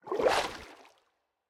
Minecraft Version Minecraft Version snapshot Latest Release | Latest Snapshot snapshot / assets / minecraft / sounds / mob / turtle / swim / swim3.ogg Compare With Compare With Latest Release | Latest Snapshot